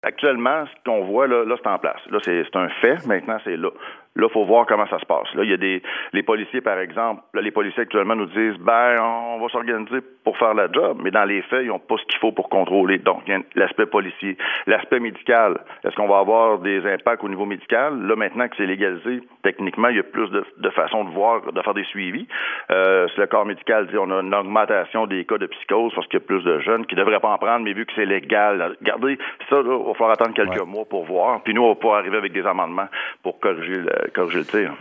Por otra parte, el diputado conservador Pierre Paul-Hus, en entrevista con la radio francesa de Radio Canadá, decía este lunes por la mañana que hay que ser realista y aceptar que no es posible criminalizar nuevamente el consumo de marihuana en el país.